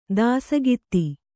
♪ dāsagitti